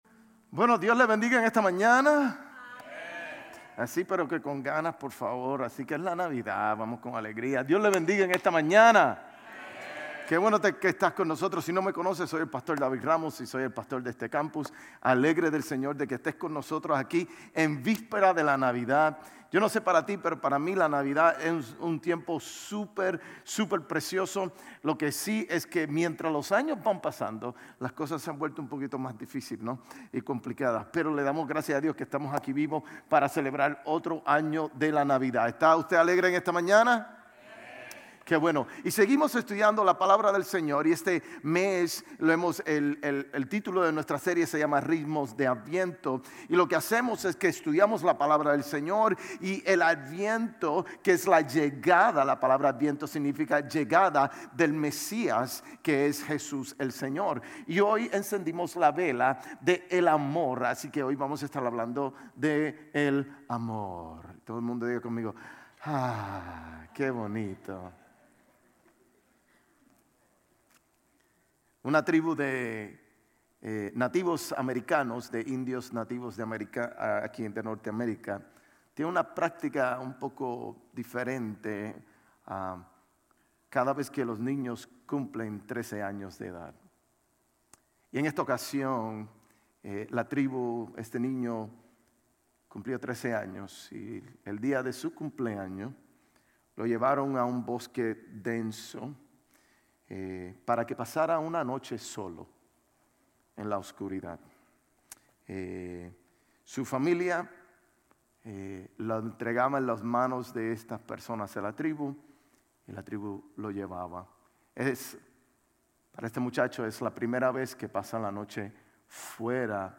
Sermones Grace Español Adviento Semana 4 | AMOR Dec 25 2024 | 00:26:57 1x 00:00 / 00:26:57 Subscribe Share RSS Feed Share Link Embed